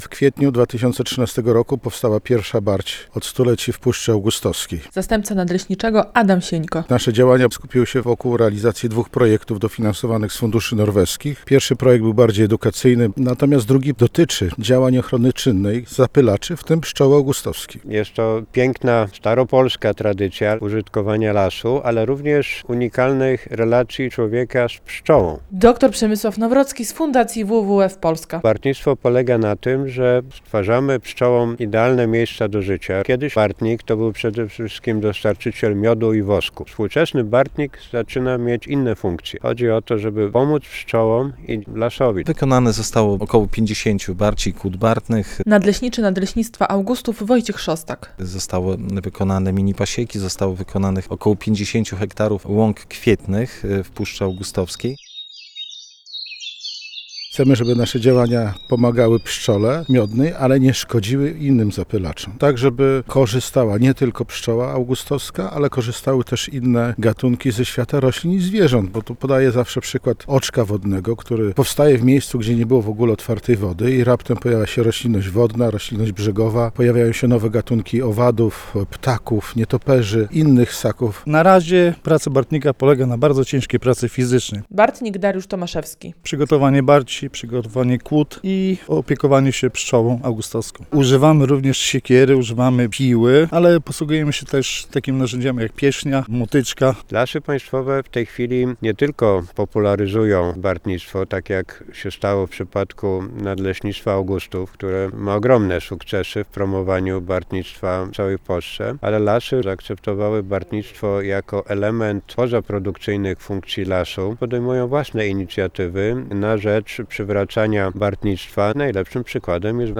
Radio Białystok | Wiadomości | Wiadomości - Dziesięciolecie bartnictwa w Puszczy Augustowskiej